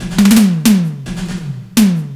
FILLTOMEL3-L.wav